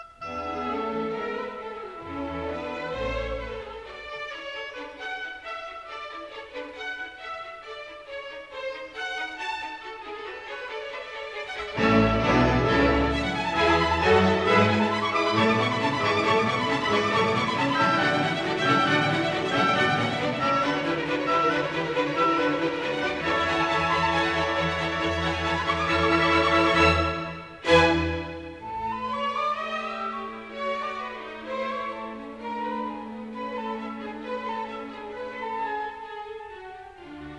vivace
stereo recording